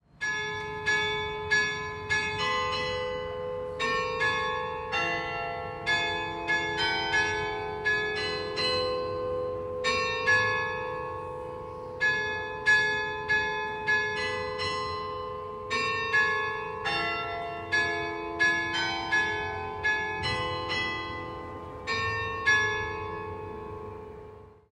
Listen to the chiming of the bells of St Fronto Cathedral, it’s as if you were there!
Carillon-cathédrale-5.mp3